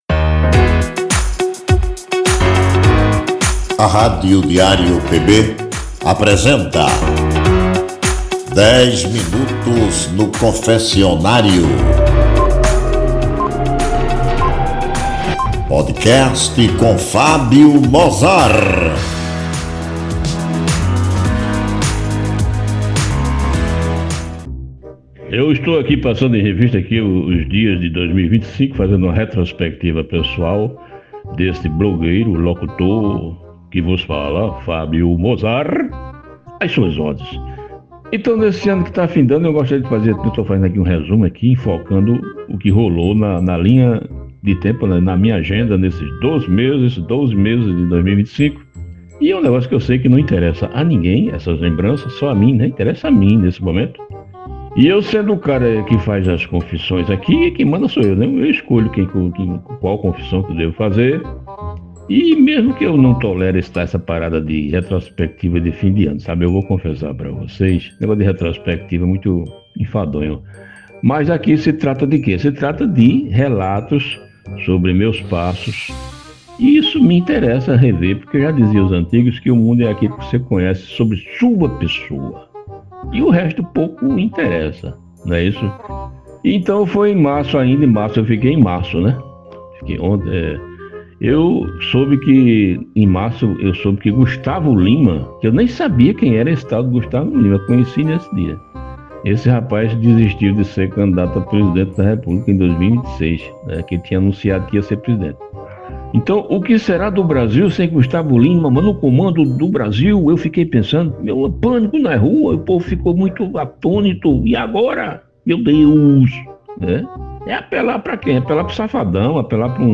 é um programa com um papo descontraído, às vezes incomum, sobre as trivialidades do nosso cotidiano.